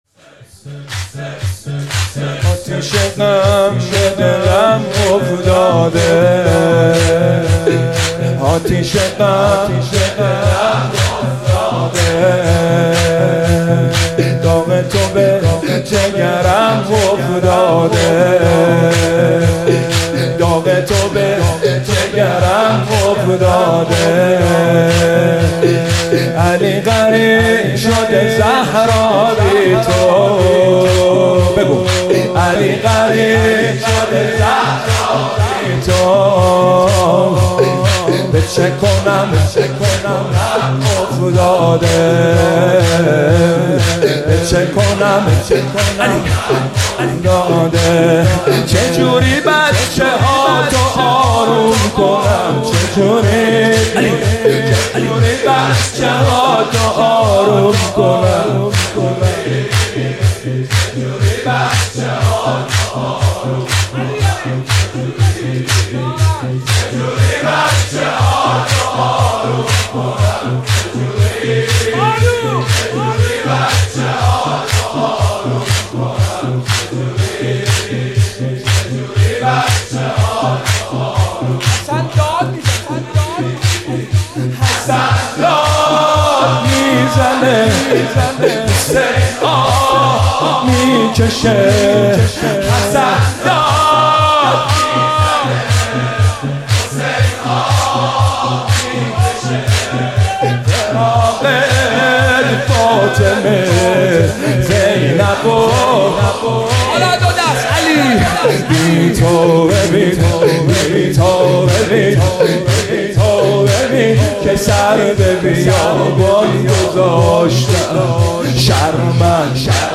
مداحی جدید
هیات روضه العباس (ع) فاطمیه دوم